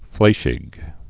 (flāshĭk)